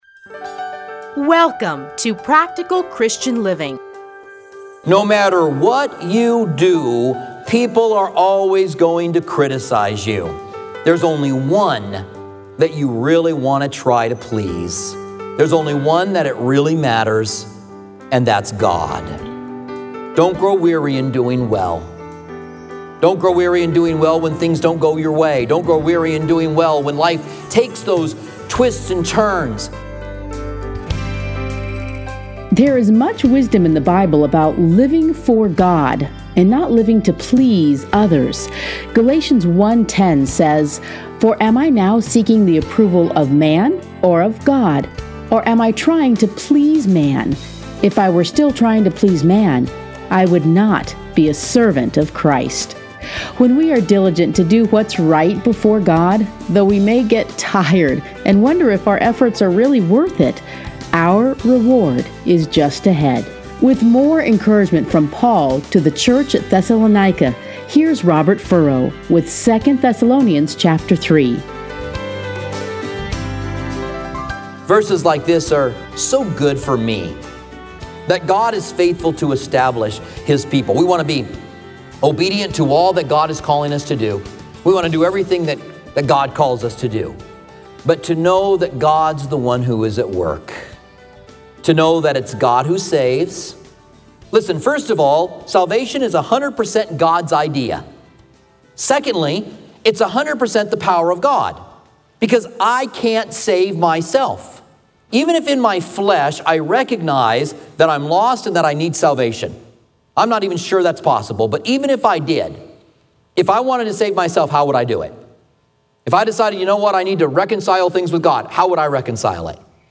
Listen here to a teaching from 2 Thessalonians.